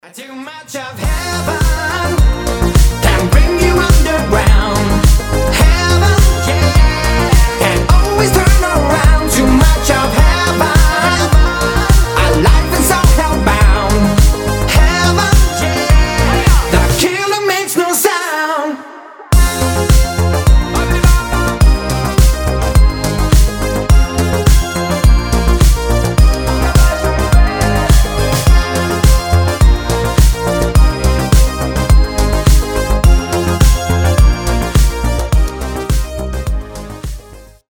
танцевальные , зажигательные , dancehall , евродэнс